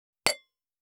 277,皿を重ねる,カチャ,ガチャン,カタッ,コトン,ガシャーン,カラン,カタカタ,チーン,カツン,カチャカチャ,ガタッ,キン,カン,コン,ゴトン,
コップ